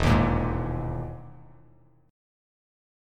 EM#11 chord